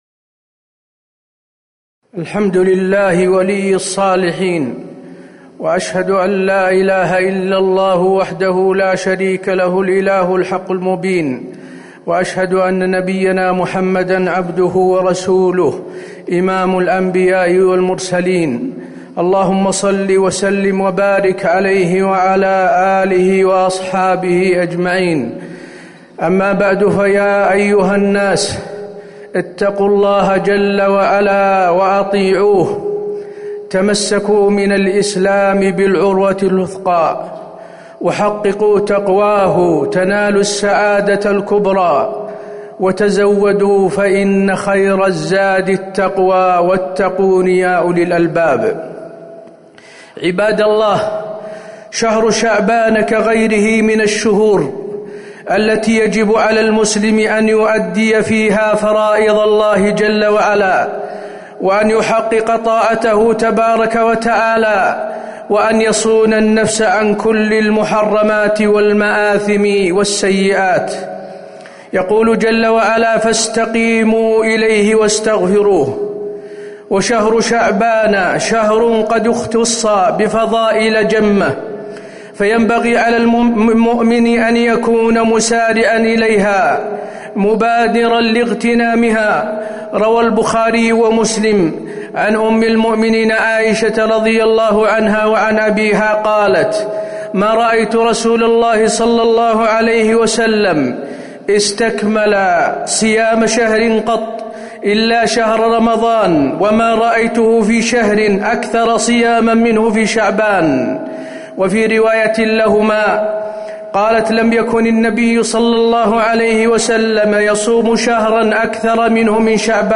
تاريخ النشر ٧ شعبان ١٤٤٠ هـ المكان: المسجد النبوي الشيخ: فضيلة الشيخ د. حسين بن عبدالعزيز آل الشيخ فضيلة الشيخ د. حسين بن عبدالعزيز آل الشيخ فضل شهر شعبان